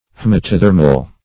Haematothermal \H[ae]m`a*to*ther"mal\ (-mal), a. Warm-blooded; homoiothermal.